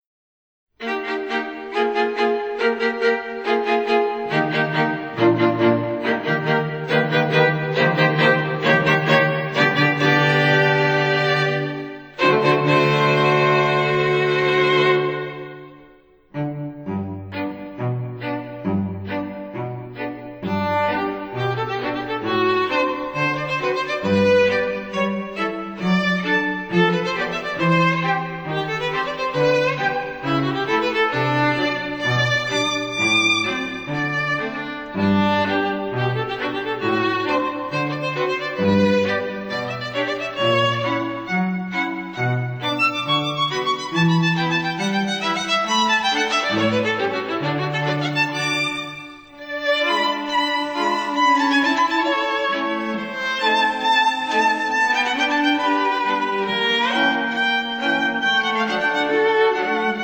以吉他、鋼琴、薩克斯風、長笛、弦樂四重奏，活化感官細膩度。